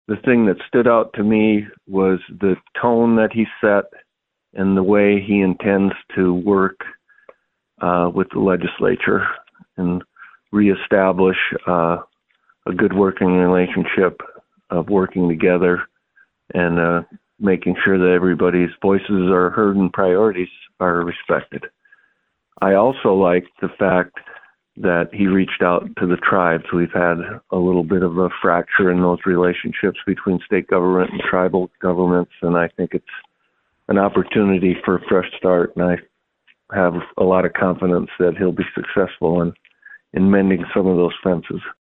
As the Senate Majority Leader, District 24 Senator Jim Mehlhaff of Pierre will work with Rhoden’s team on republican party priorities. Mehlhaff appreciated Rhoden’s interest in rebuilding relationships.